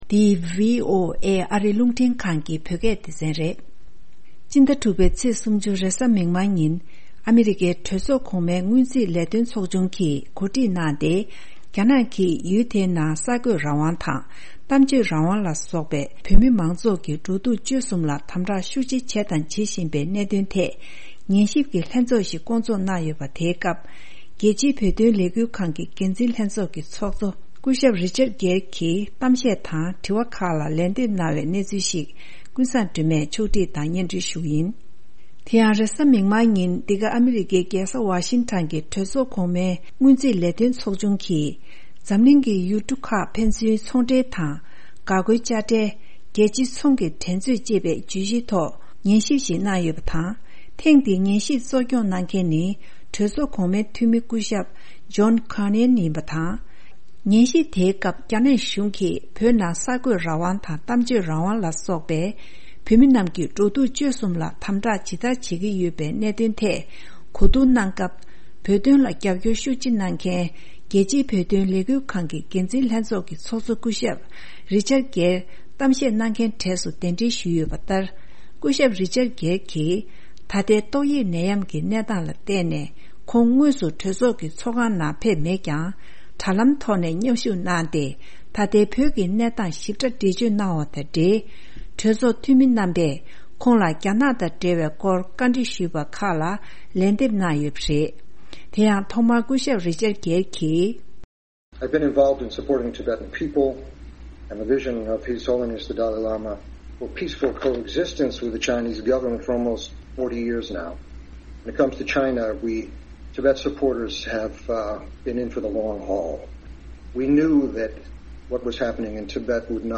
Richard Gere Testifies before Senate finance committee